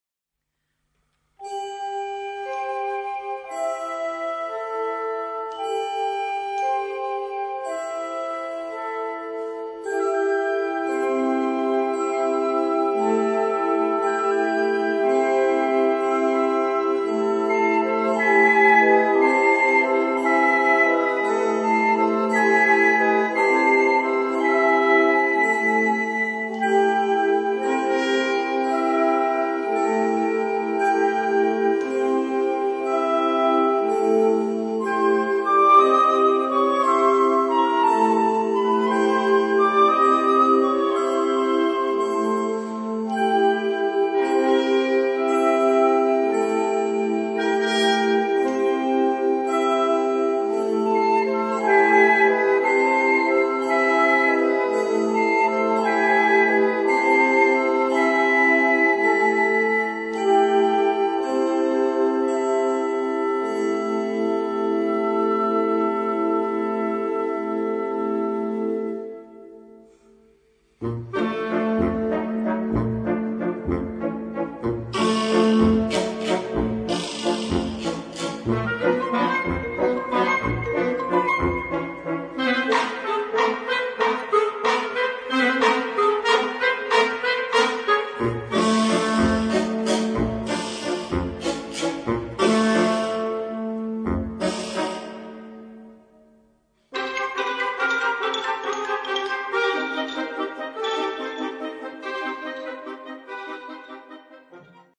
Partitions pour orchestre d'harmonie et fanfare.